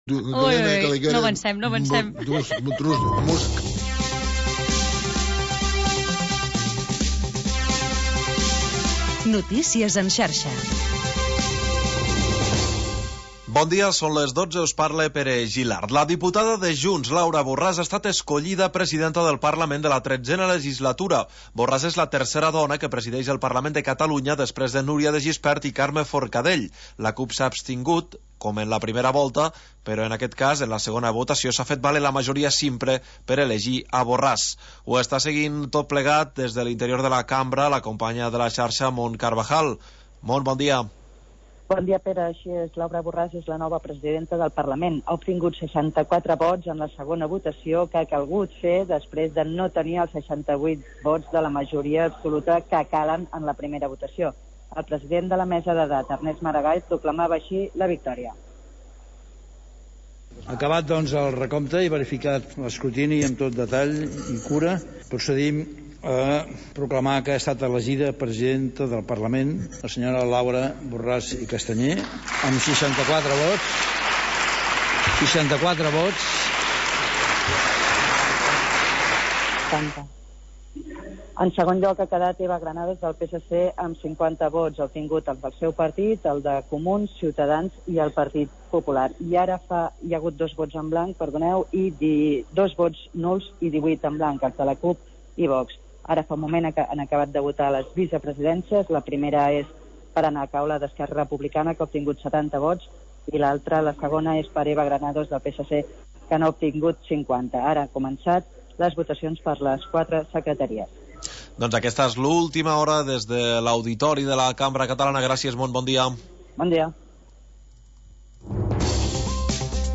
Tercera hora musical del magazín local d'entreteniment